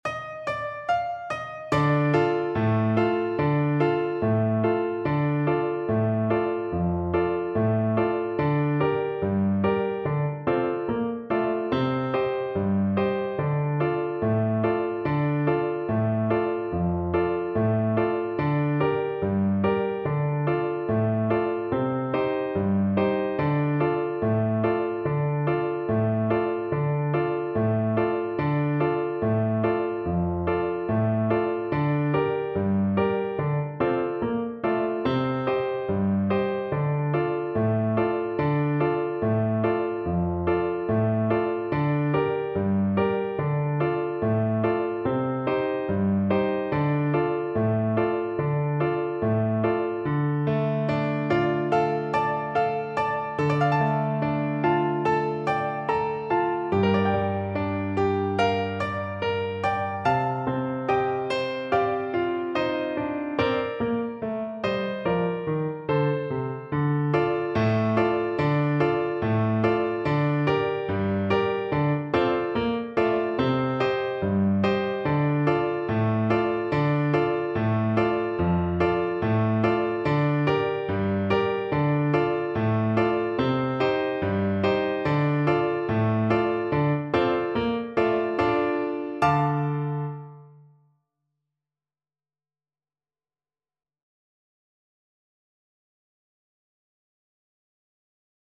Flute
Traditional Music of unknown author.
Bb major (Sounding Pitch) (View more Bb major Music for Flute )
2/4 (View more 2/4 Music)